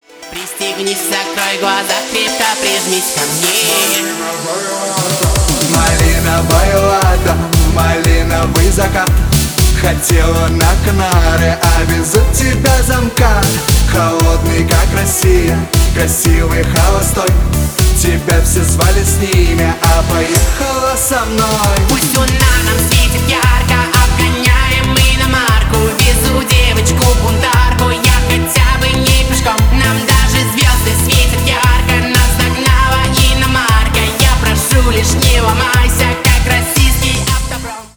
клубные
поп